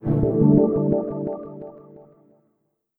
Windows X13 Startup.wav